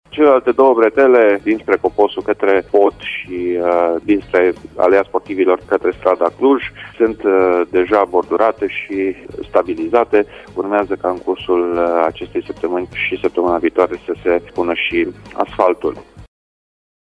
Lucrările avansează la Pasajul Michelangelo din Timişoara. Viceprimarul Dan Diaconu a anunţat, la Radio Timişoara, că muncitorii au ajuns până la jumătatea pasajului cu eliminarea pământului din interiorul subpasajului.